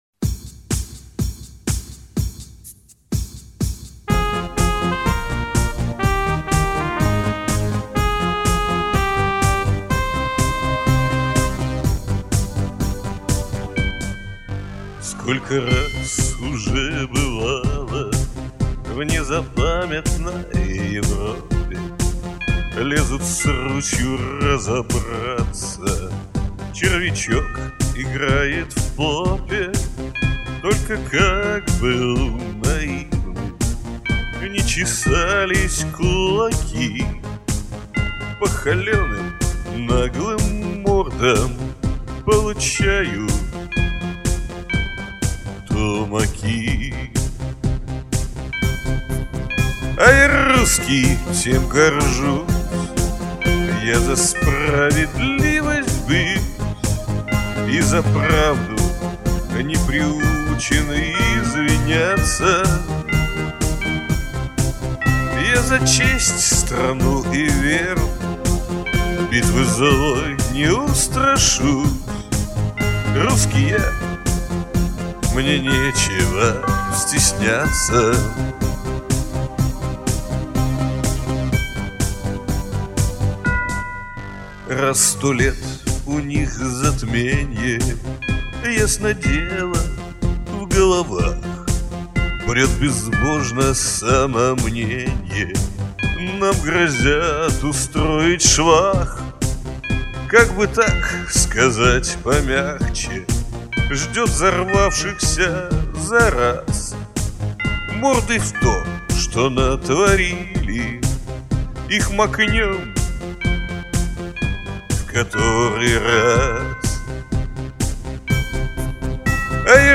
Жанр: Русский поп-шансон